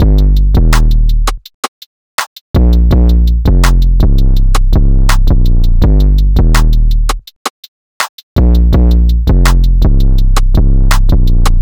drumloop 6 (165 bpm).wav